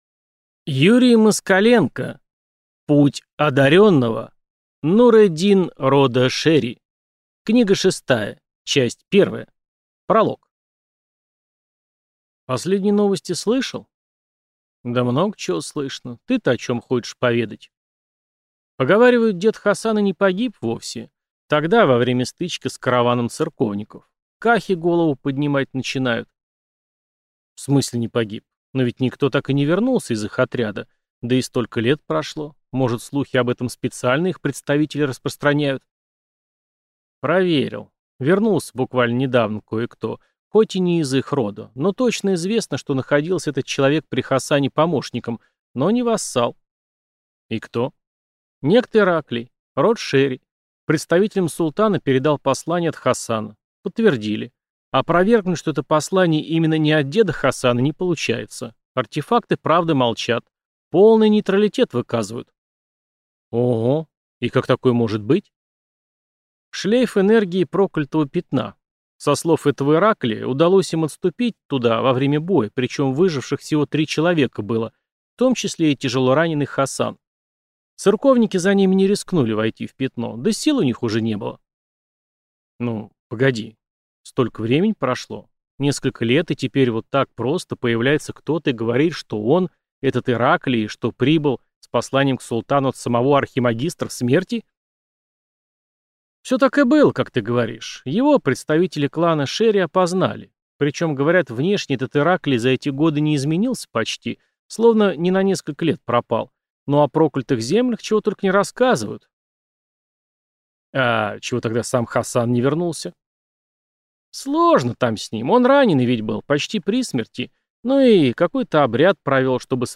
Аудиокнига Путь одарённого. Нур-эдин рода Шери. Книга шестая. Часть первая | Библиотека аудиокниг